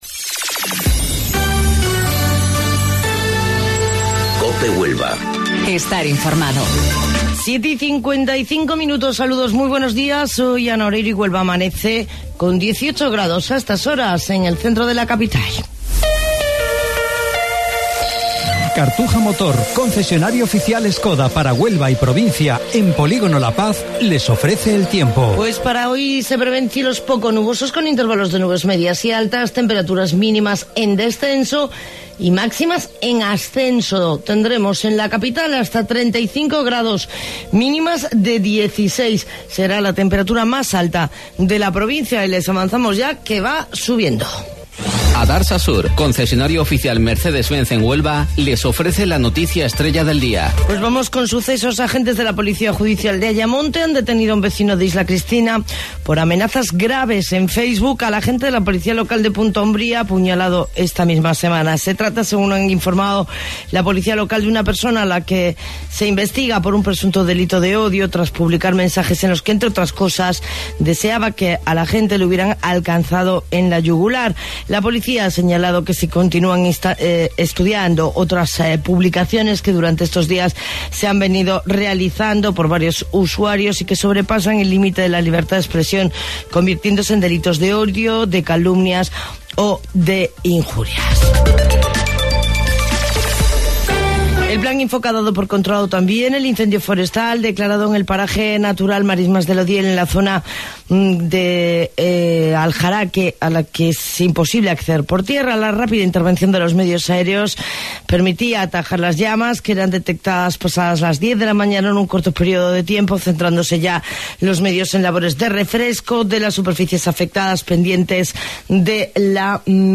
AUDIO: Informativo Local 07:55 del 12 de Agosto